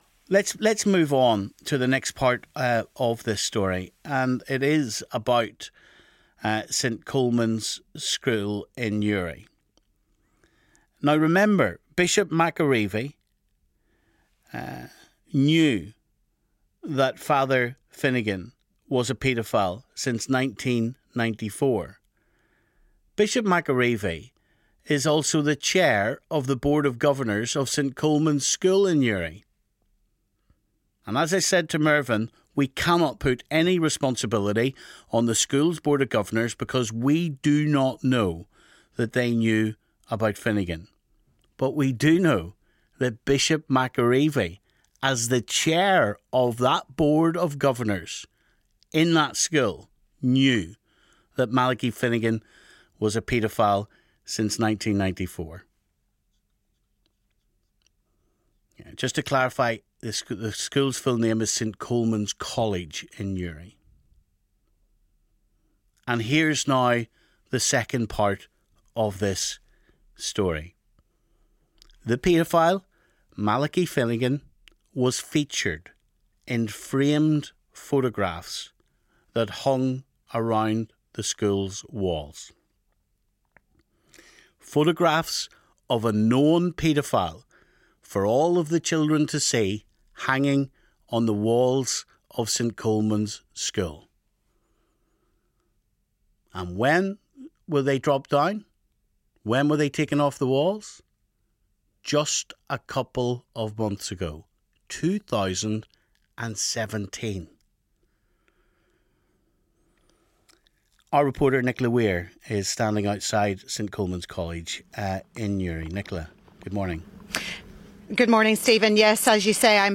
reports from outside St Colman's College Grammar school in Newry